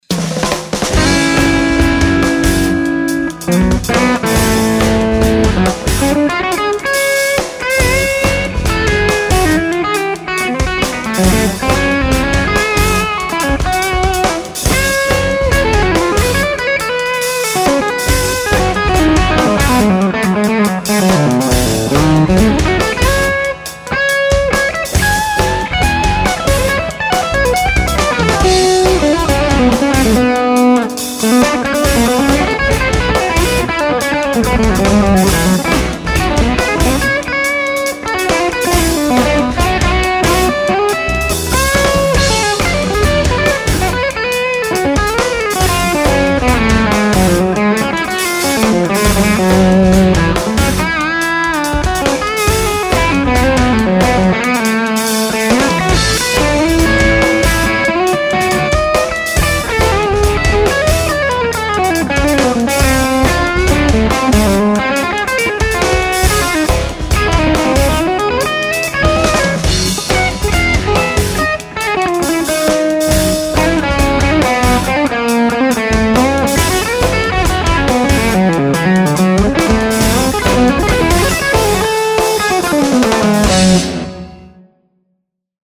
Clip 2 - V1 and V2 both JJ 12ax7.
Same exact settings and mic between clips.
The JJ sounded fatter...RFT more clarity.
The JJs seem to not have that hair at the attack, but a nice little thump instead.
The second seemed to have a low mid content that was there but sounded a little smudgy, gave it a somewhat smoother vibe but lacked a good definition in that area.